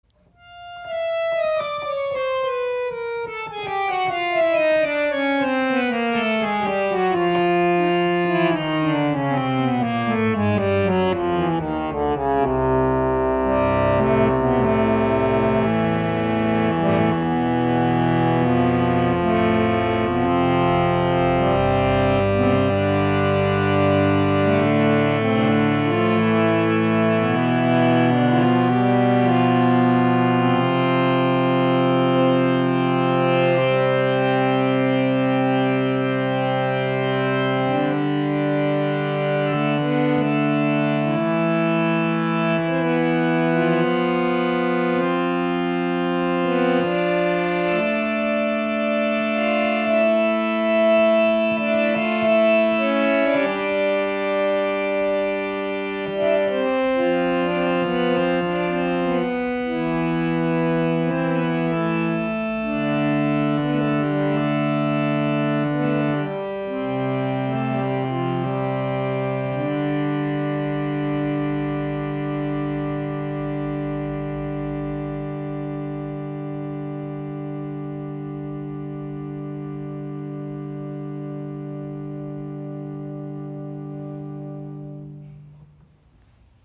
Das Bhava Limited Edition Harmonium kombiniert schicke Designelemente mit einer Zedernholzkonstruktion und hat einen warmen Klang und ein unverwechselbares Aussehen.
Wenn es um einen unglaublich langen, kompromissloses Sustain, sanftes Spiel und reiche, dynamische Klangvielfalt geht, ist das Bhava Studio unübertroffen.
Anschläge: 5 (Male, Bass, Bass, Tremolo, Male)
Bordune: 4 (C, D, G, A)
Stimmung: Concert Pitch / 440 Hz